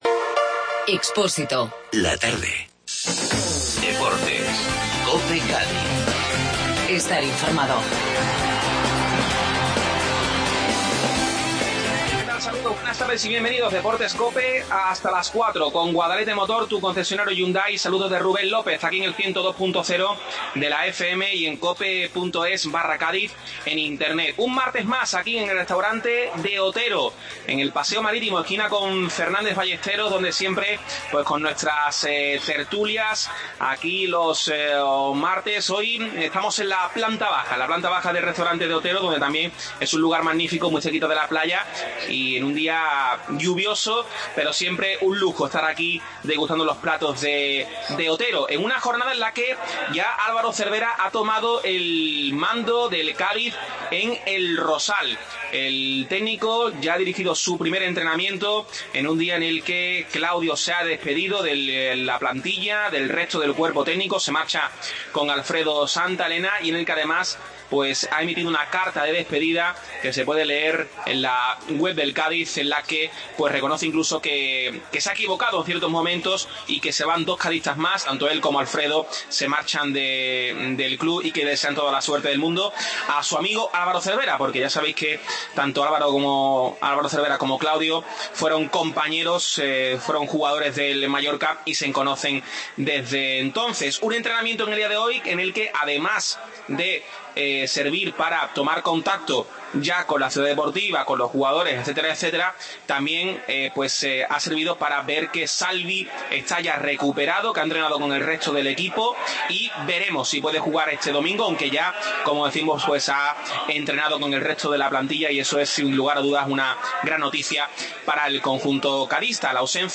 Desde el Restaurante De Otero